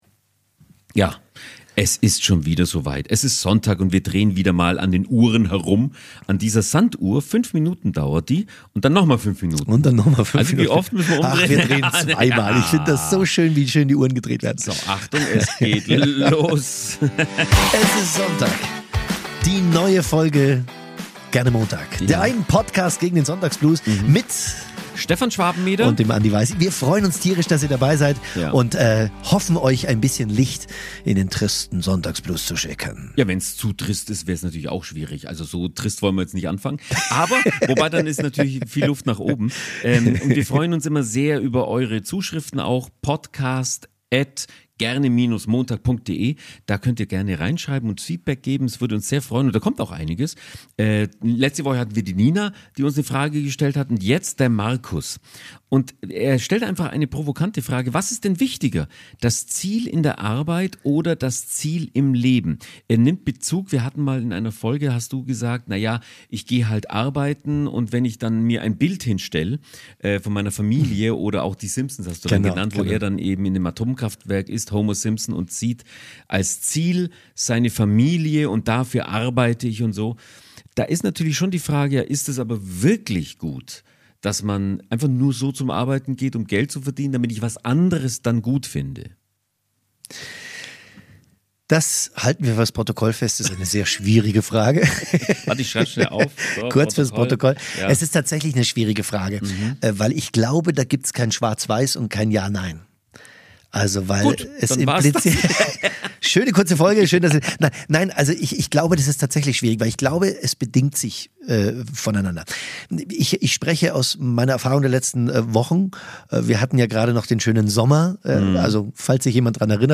Freut euch außerdem auf einen energiegeladenen Motivations-Rap, den sich die KI-Montagsfreunde ausgesucht haben.